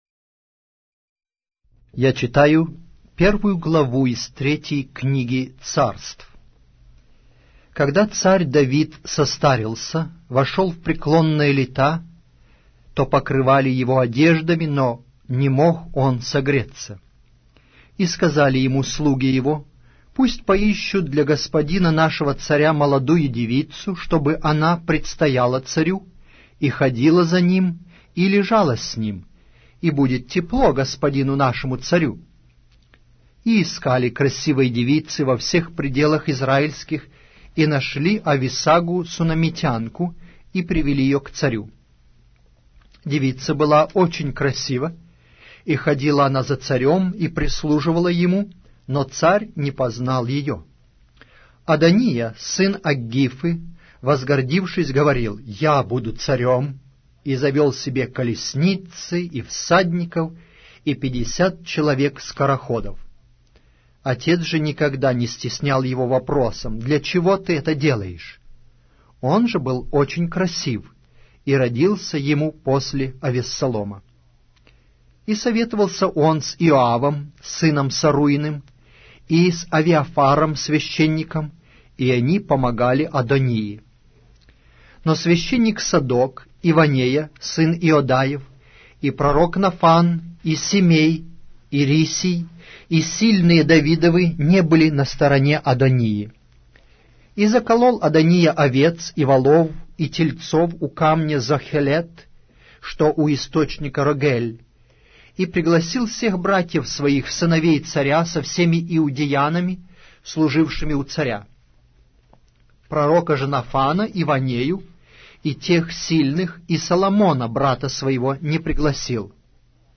Аудиокнига: 3-я Книга Царств